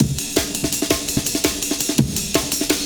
cw_amen03_167.wav